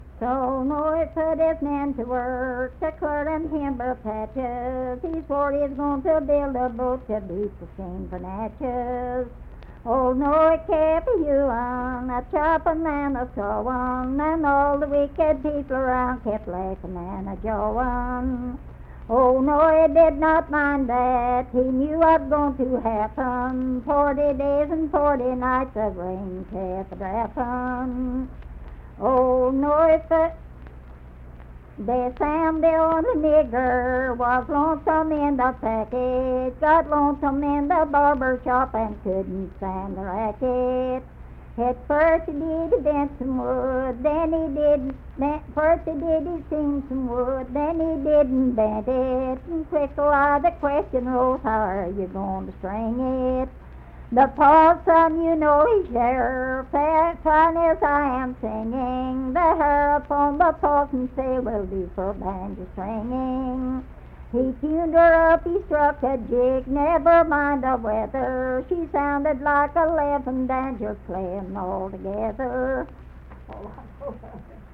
Unaccompanied vocal music
Hymns and Spiritual Music
Voice (sung)
Logan County (W. Va.)